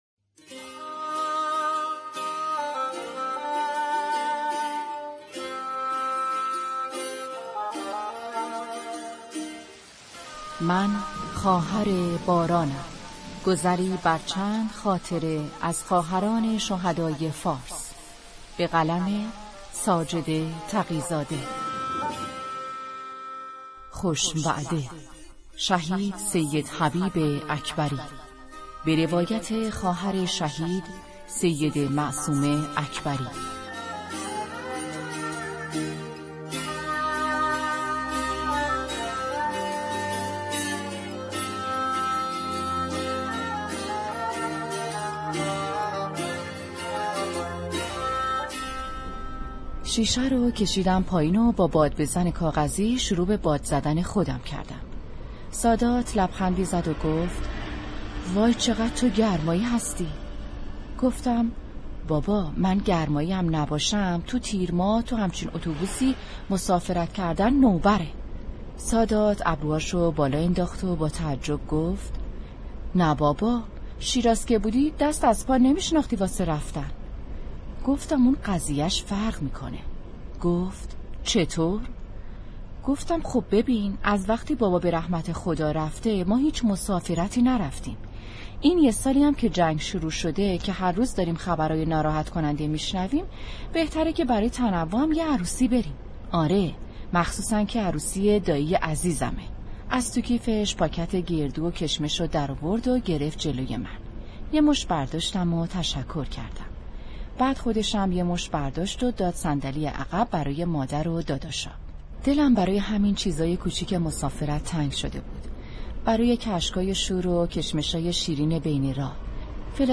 کتاب صوتی «من خواهر بارانم» بخش ششم